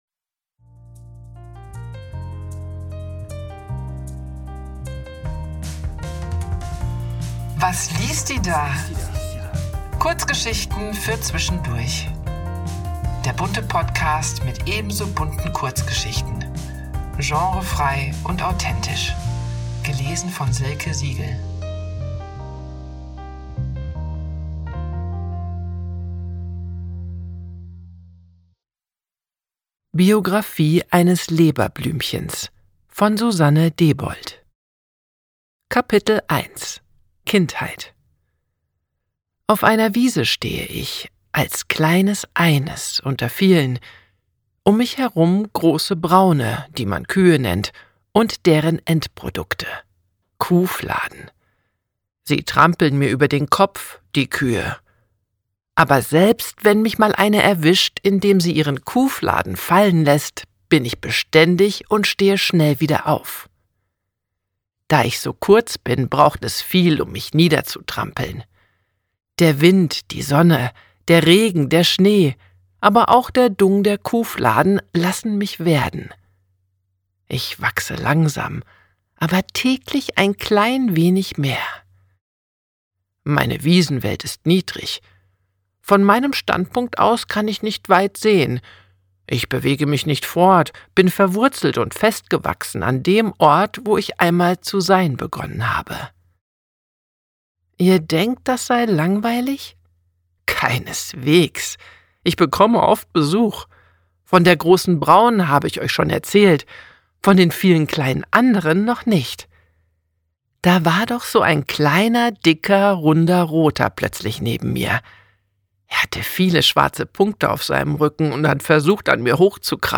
Was denkt und macht eigentlich ein Leberblümchen? Gefühlvolle Interpretation eins Blumenlebens.